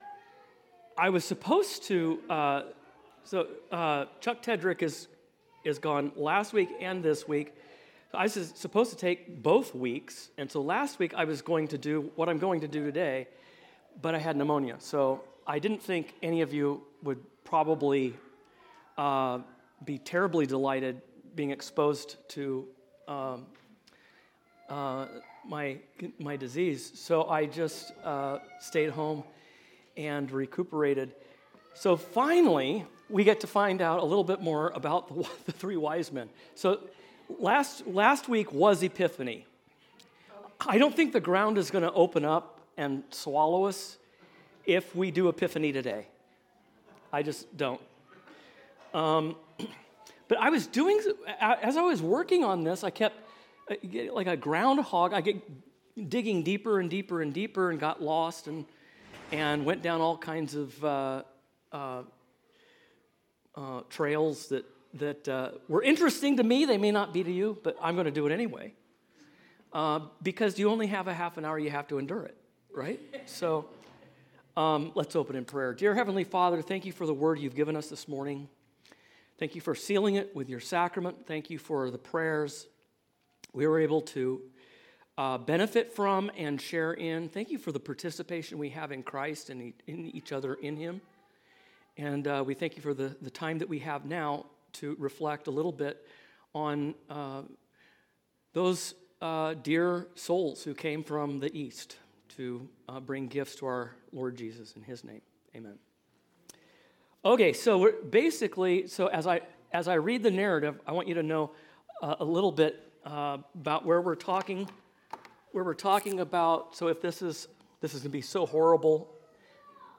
Lectures taught at CURC